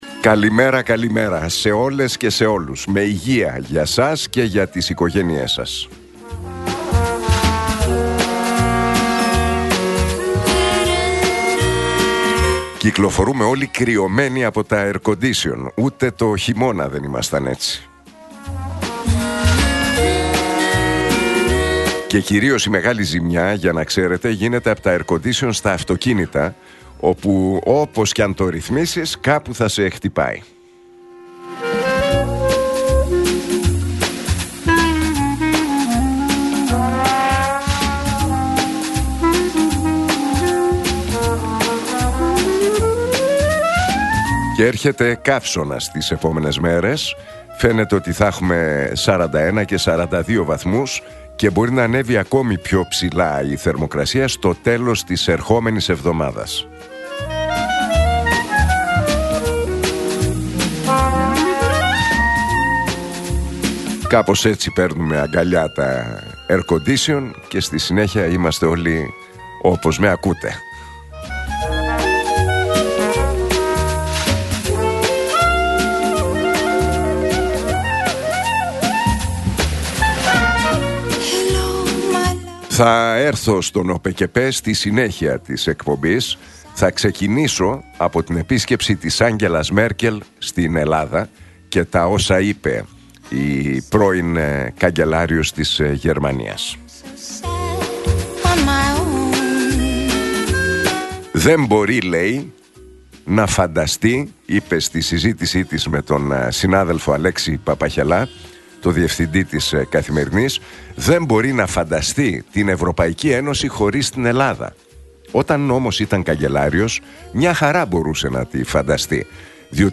Ακούστε το σχόλιο του Νίκου Χατζηνικολάου στον ραδιοφωνικό σταθμό Realfm 97,8, την Πέμπτη 3 Ιουλίου 2025.